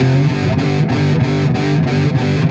AM_HeroGuitar_95-G01.wav